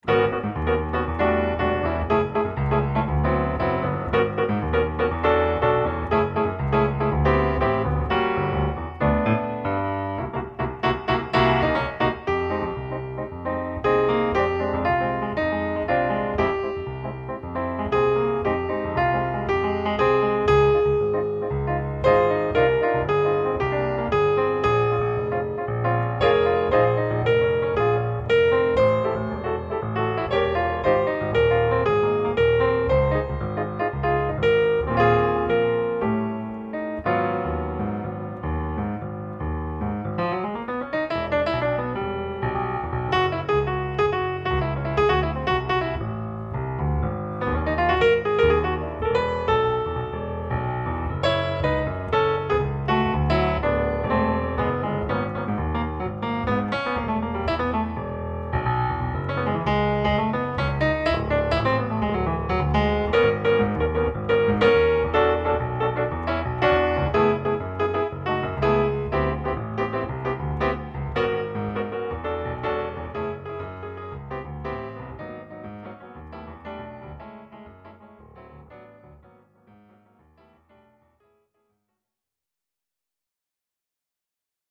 Samples of Jazz Playing & Arranging
Solos
(samba)